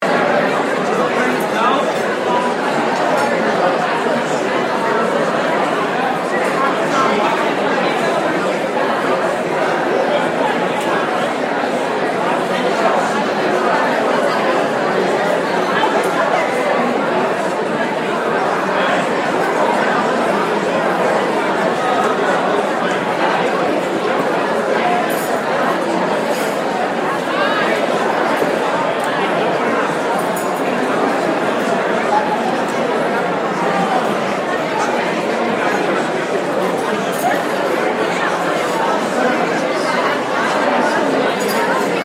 Вечеринка и карнавал - гул шумного клуба, певец еще не на сцене